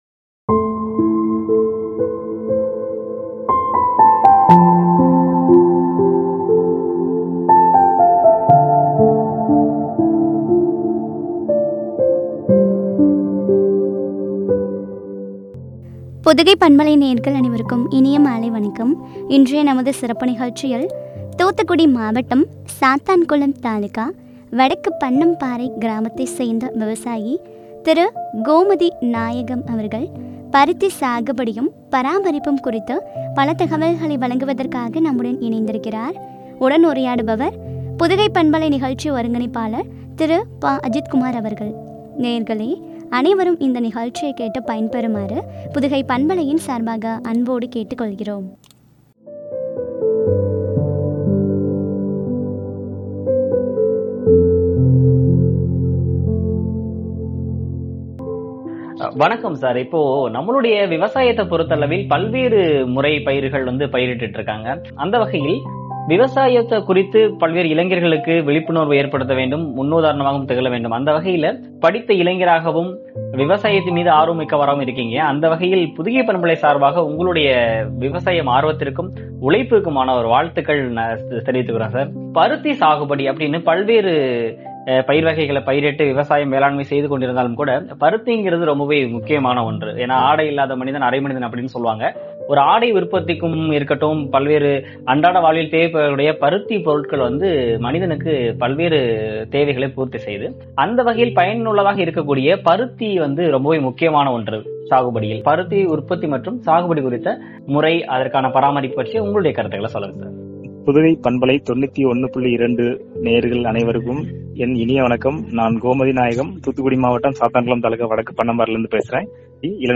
பருத்தி சாகுபடியும், பராமரிப்பும் பற்றிய உரையாடல்.